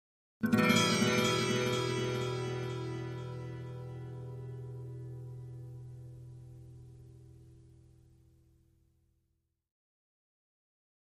Acoustic Guitar - Minor Chord 3 - Strong Echo And Flange